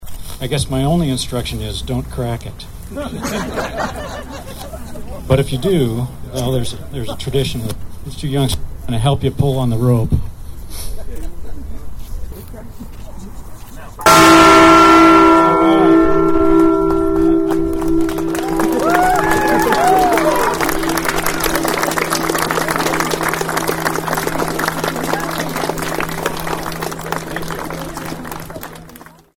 PIERRE, (KCCR) — Approximately 100 people consisting of lawmakers, school children and citizens gathered around South Dakota’s Liberty Bell to hear the bell ring for the first time since it’s installation next to the Soldiers and Sailors Building in Pierre back in the 1950’s….